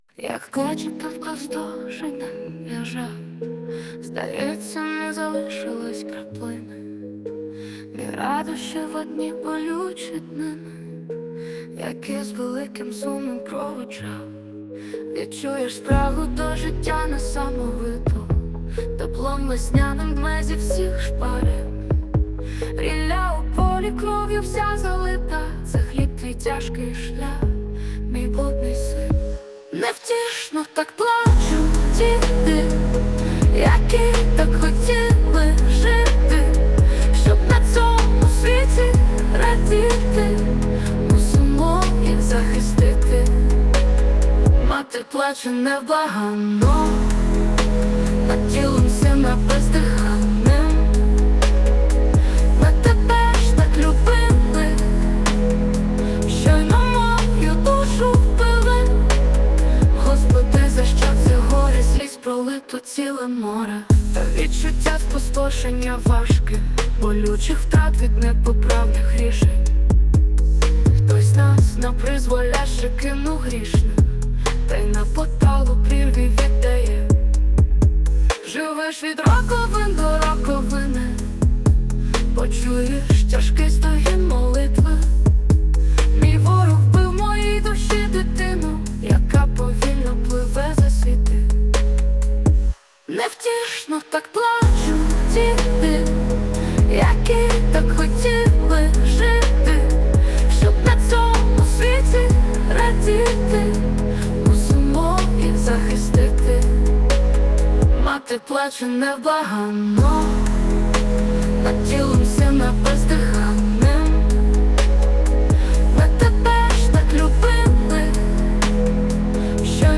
ТИП: Пісня
СТИЛЬОВІ ЖАНРИ: Драматичний